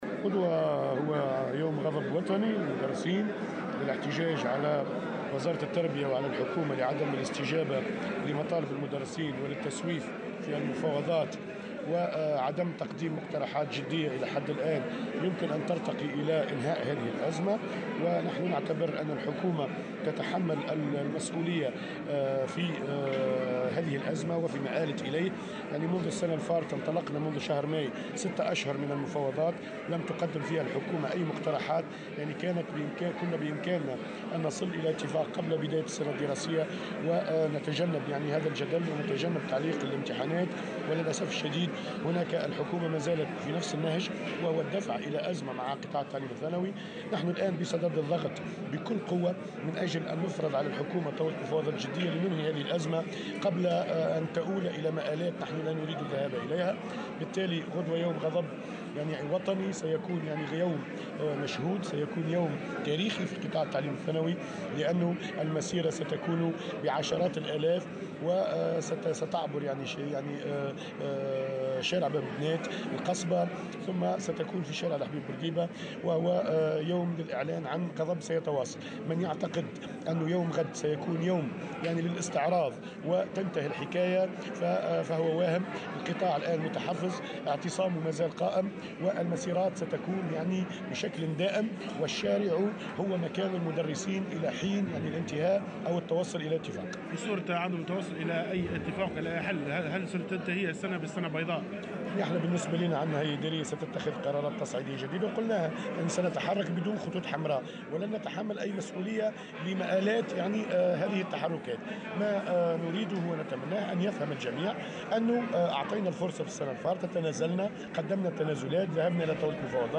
على هامش اجتماع للمدرسين في المهدية